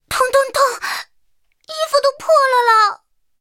SU-5中破语音.OGG